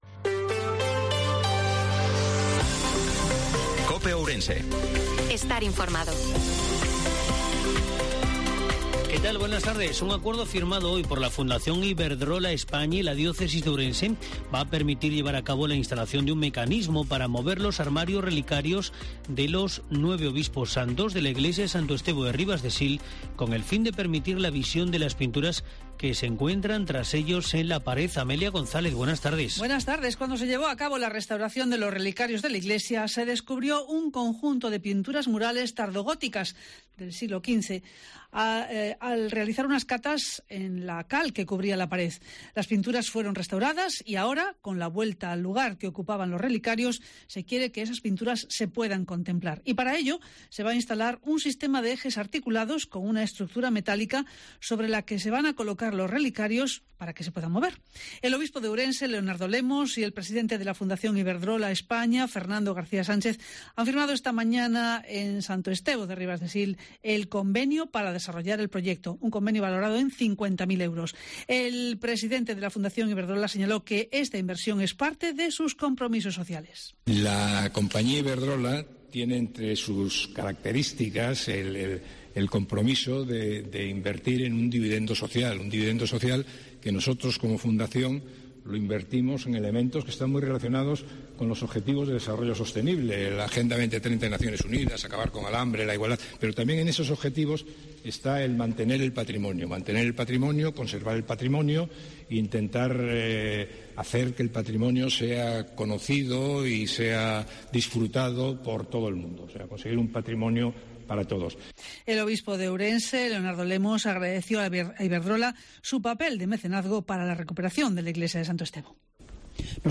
INFORMATIVO MEDIODIA COPE OURENSE-11/10/2022